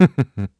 Riheet-Vox-Laugh_kr.wav